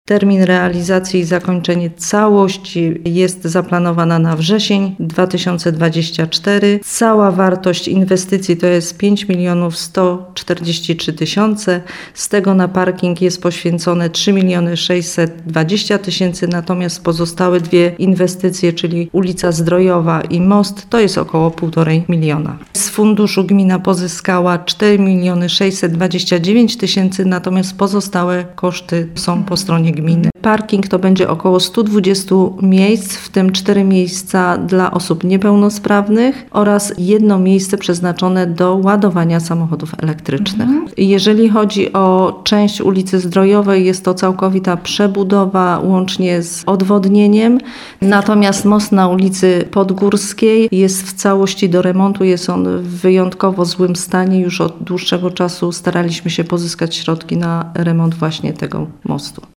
O inwestycji mówi Anna Skotnicka-Nędzka, pełniąca funkcję wójta gminy Jaworze.